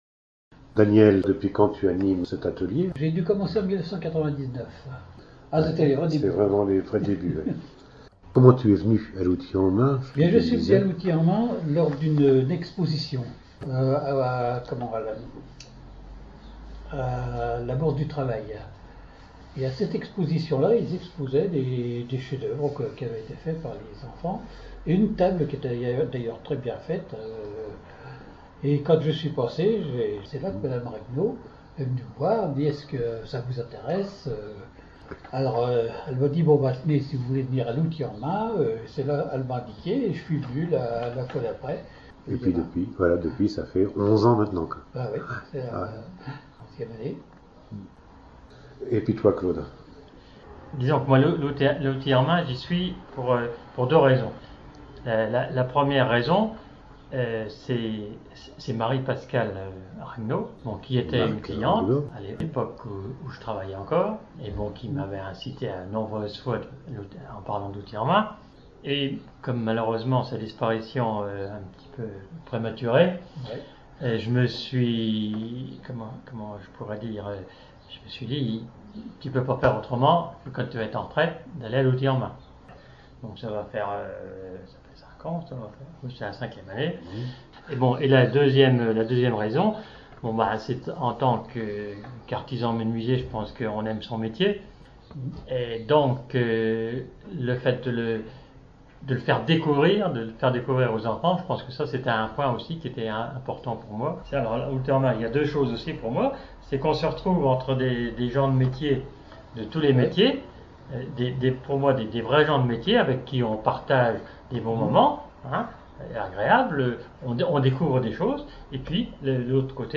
Cliquez sur le lecteur ci-dessous pour écouter nos menuisiers :
Duo de menuisiers...
entretien_menuisiers.mp3